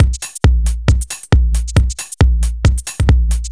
Index of /musicradar/uk-garage-samples/136bpm Lines n Loops/Beats